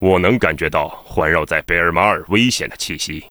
文件 文件历史 文件用途 全域文件用途 Bk_tk_03.ogg （Ogg Vorbis声音文件，长度3.3秒，109 kbps，文件大小：44 KB） 源地址:游戏语音 文件历史 点击某个日期/时间查看对应时刻的文件。